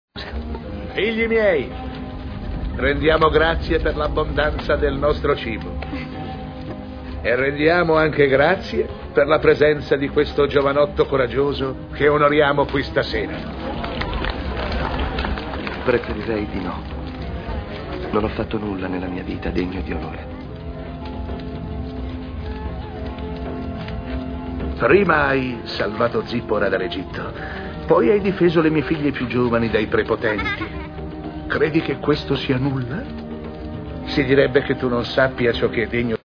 voce di Maurizio Mattioli nel film d'animazione "Il principe d'Egitto", in cui doppia Ietro.